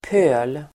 Ladda ner uttalet
Uttal: [pö:l]